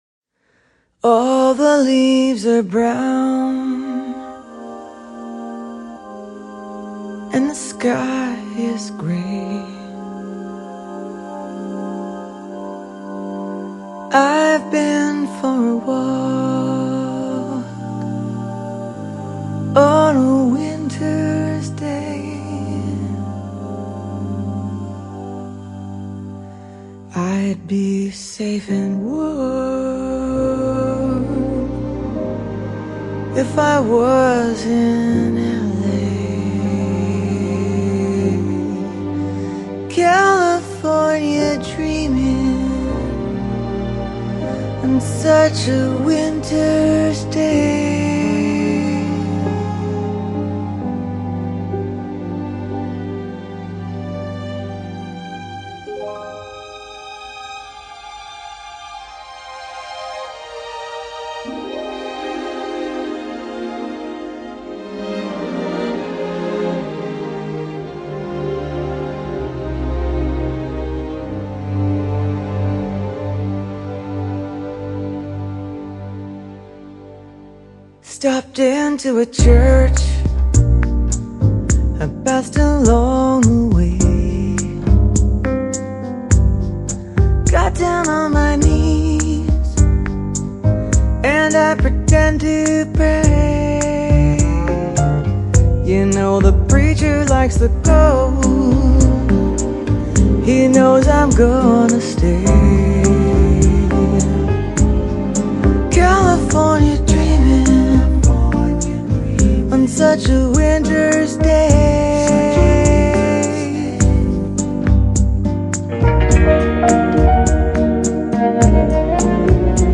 Jazz, Pop